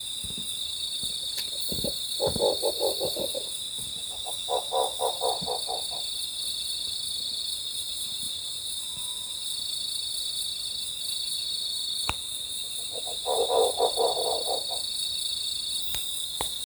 Lechuza Listada (Strix hylophila)
Nombre en inglés: Rusty-barred Owl
Localidad o área protegida: Parque Nacional Iguazú
Condición: Silvestre
Certeza: Fotografiada, Vocalización Grabada
Lechuza-listada.mp3